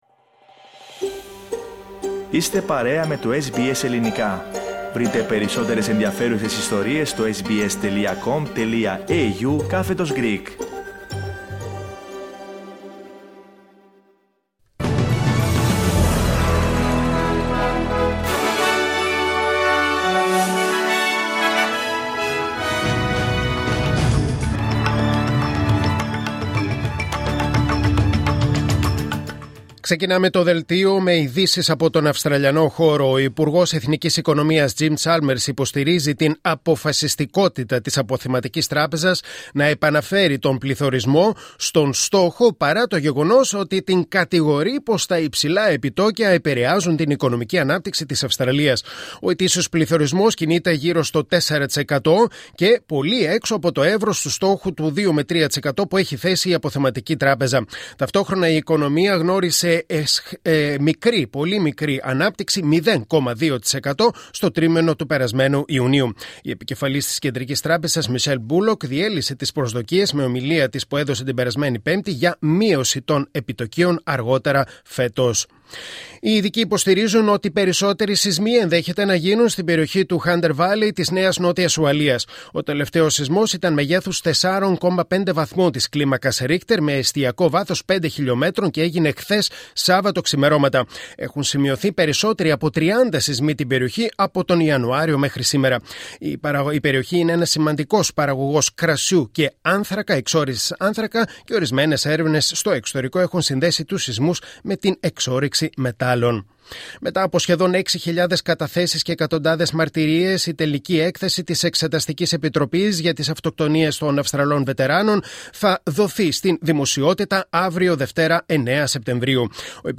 Δελτίο Ειδήσεων Κυριακή 8 Σεπτεμβρίου 2024